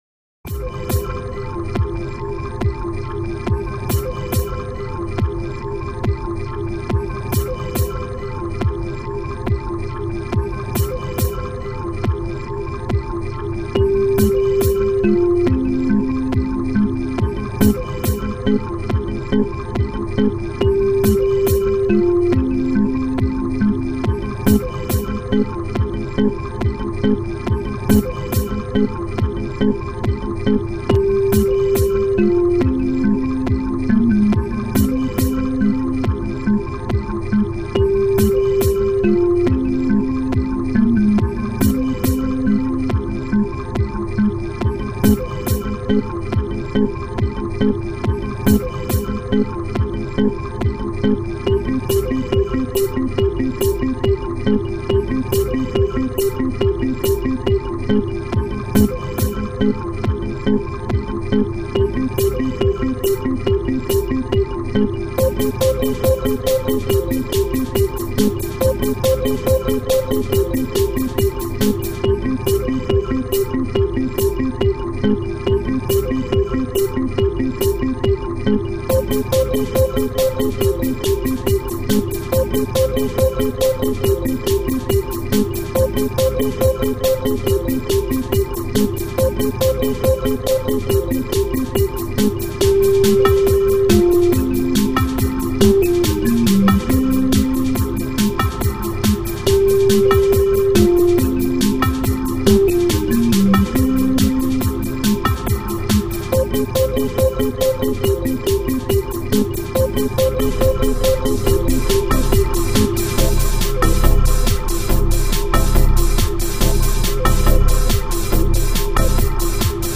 dance/electronic
Ambient